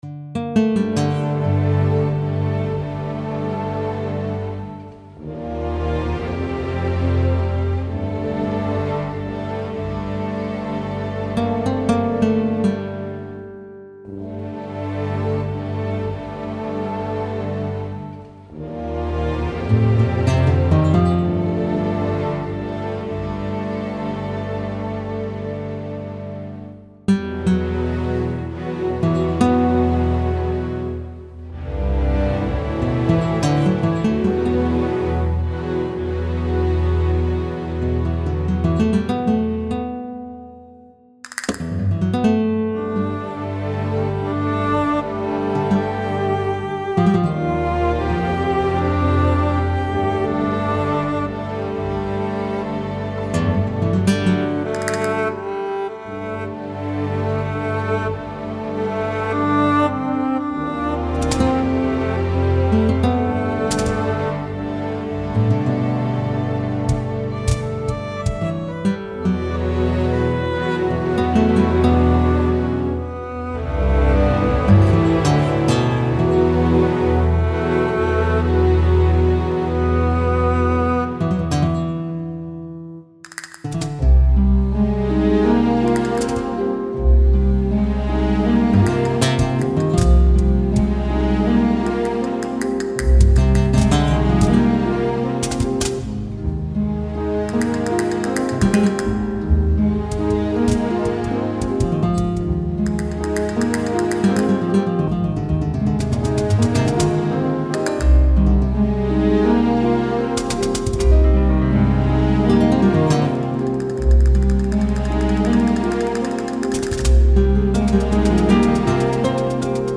banda sonora
castañuela
melodía